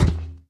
Sound / Minecraft / mob / irongolem / walk4.ogg
Current sounds were too quiet so swapping these for JE sounds will have to be done with some sort of normalization level sampling thingie with ffmpeg or smthn 2026-03-06 20:59:25 -06:00 8.6 KiB Raw History
walk4.ogg